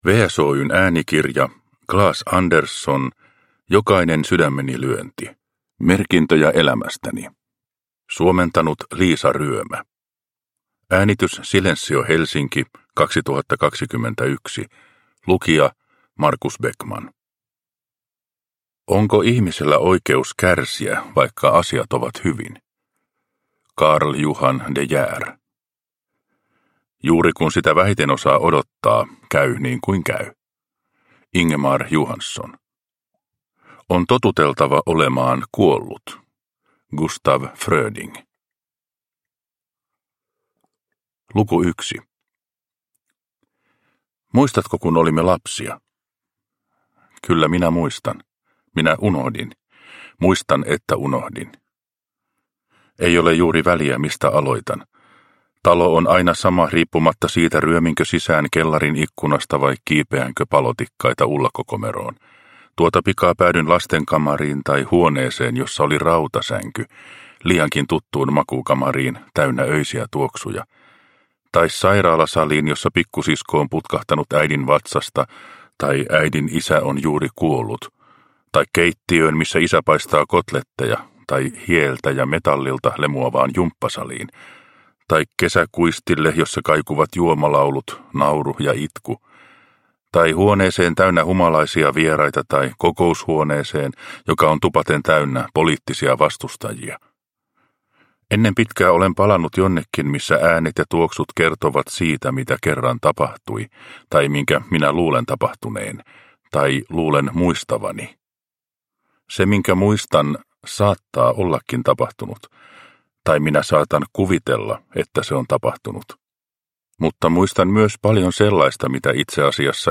Jokainen sydämeni lyönti – Ljudbok – Laddas ner